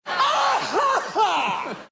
Play Risa Jim Carrey - SoundBoardGuy
risa-jim-carrey.mp3